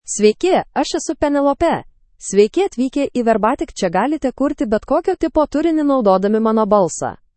FemaleLithuanian (Lithuania)
PenelopeFemale Lithuanian AI voice
Penelope is a female AI voice for Lithuanian (Lithuania).
Voice sample
Listen to Penelope's female Lithuanian voice.
Penelope delivers clear pronunciation with authentic Lithuania Lithuanian intonation, making your content sound professionally produced.